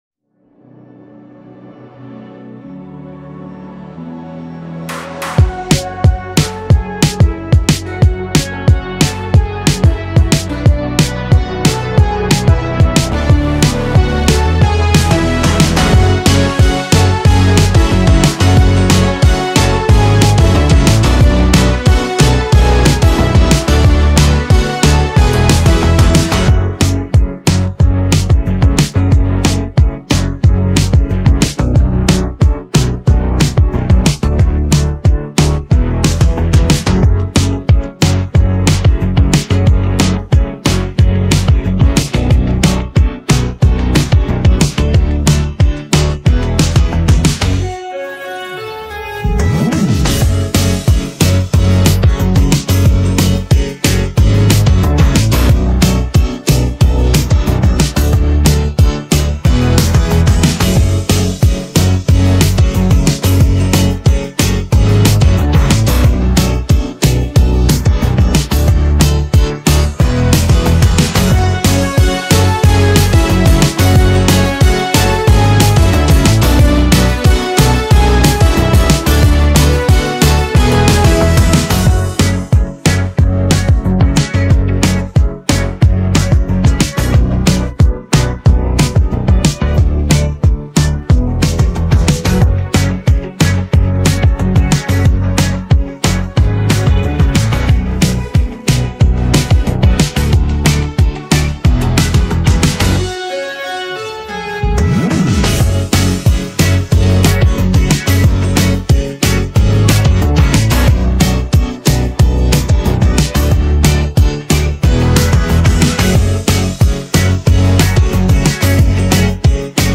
freestyle beat
Download and make awesome music with this free instrumental.